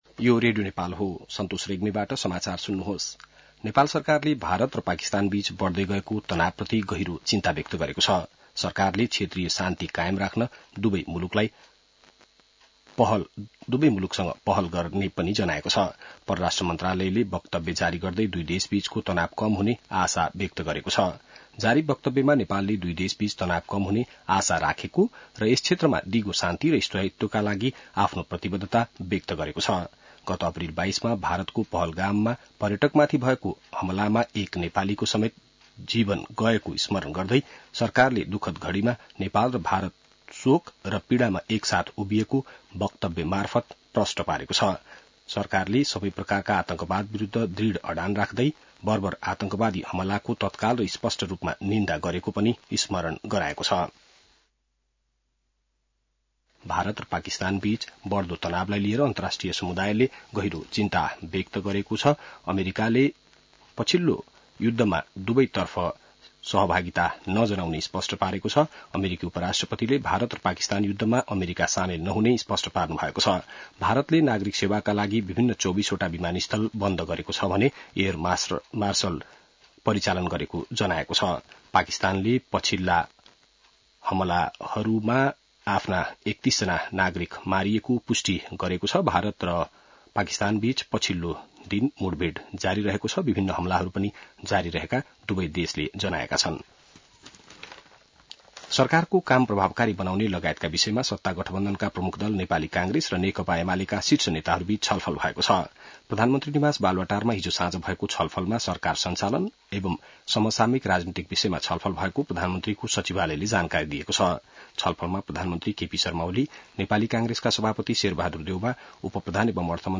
बिहान ६ बजेको नेपाली समाचार : २६ वैशाख , २०८२